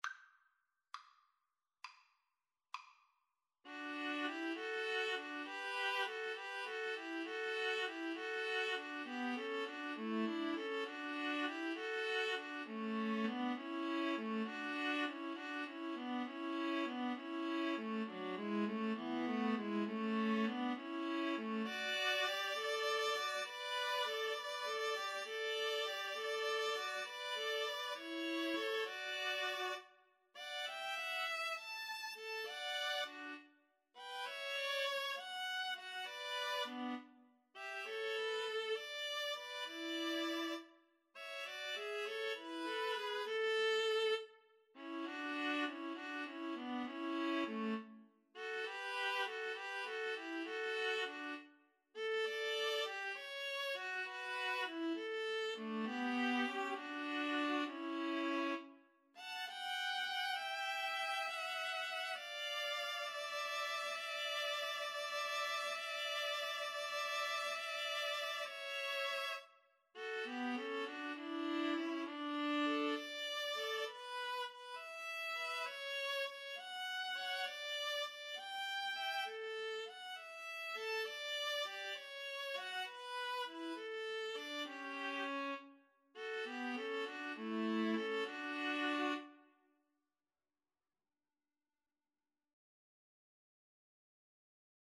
Free Sheet music for Viola Trio
D major (Sounding Pitch) (View more D major Music for Viola Trio )
Largo
Viola Trio  (View more Intermediate Viola Trio Music)
Classical (View more Classical Viola Trio Music)